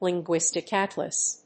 linguístic átlas